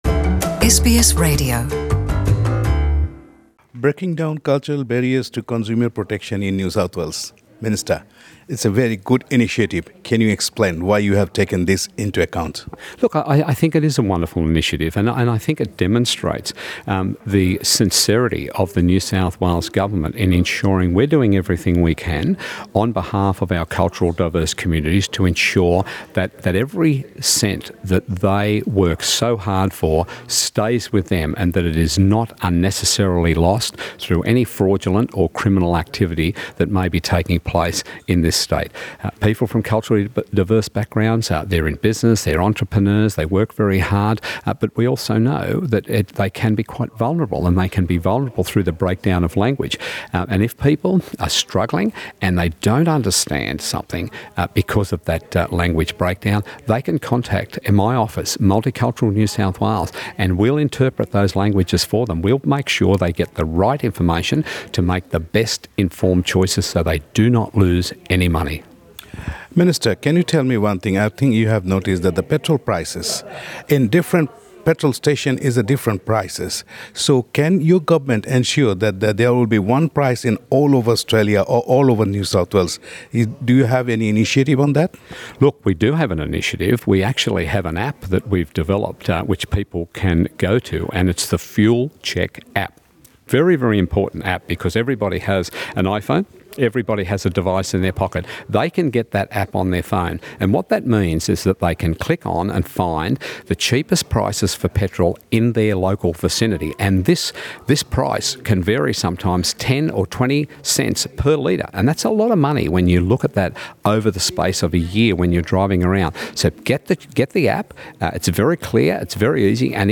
Interview with Ray Williams, Minister for Multiculturalism, NSW